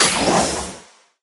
safe_open_char_01.ogg